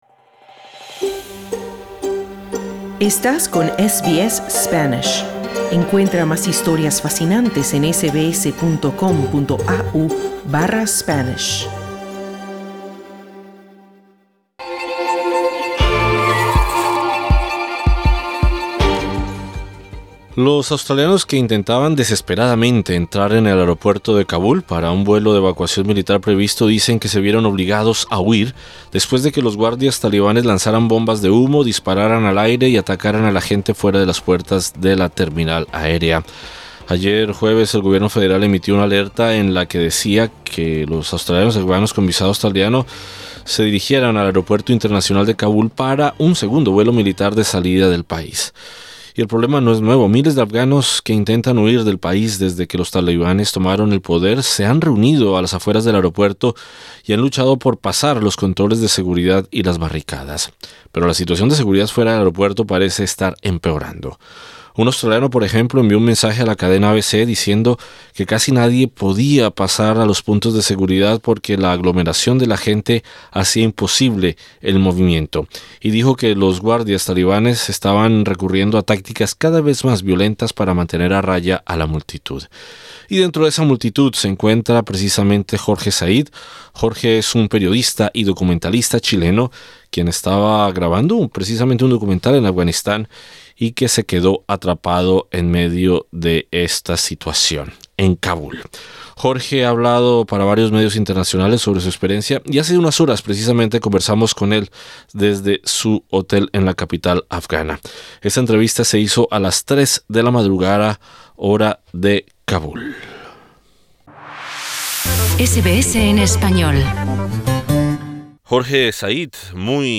El periodista chileno